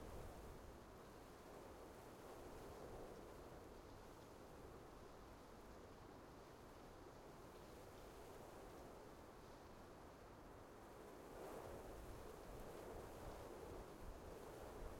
sfx_amb_combat_mountain.ogg